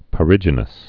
(pə-rĭjə-nəs)